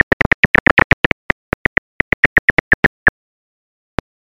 • Свой пронзительный крик они извлекают с помощью гортани.
kozhan-burij-eptesicus-fuscus.mp3